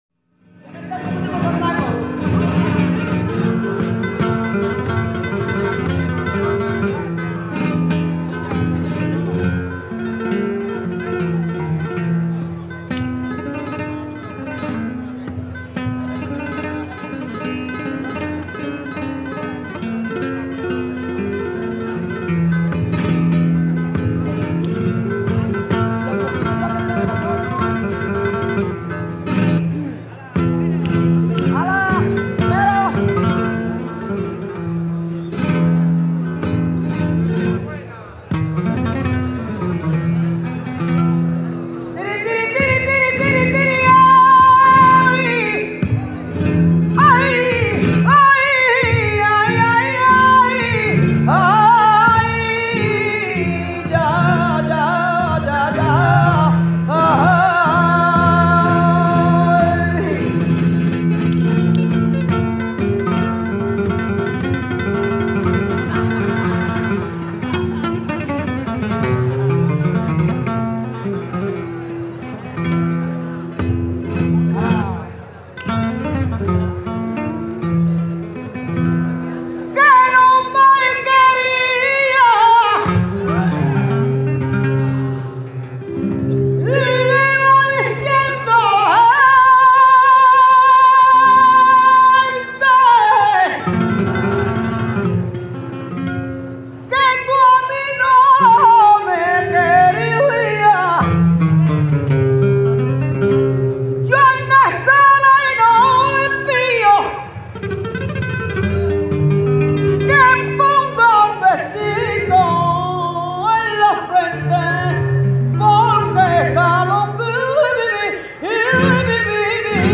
Guitare
Fandangos